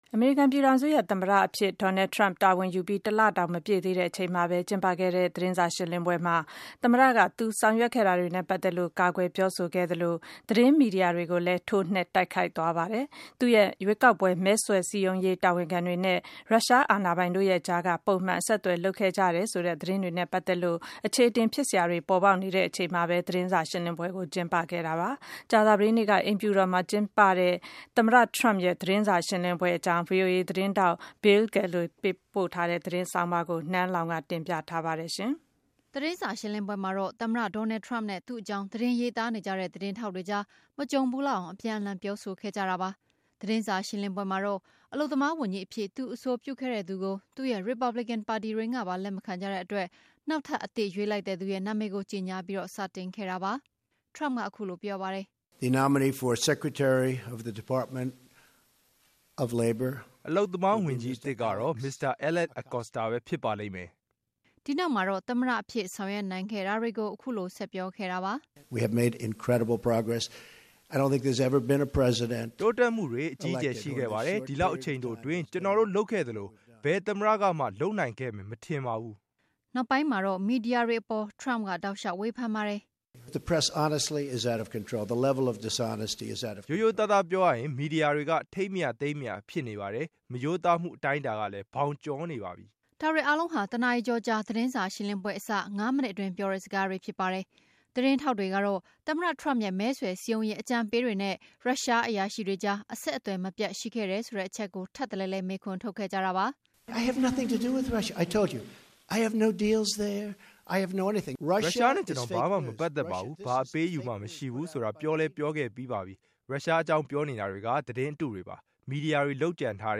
အမေရိကန်သမ္မတ Donald Trump သတင်းစာ ရှင်းလင်းပွဲ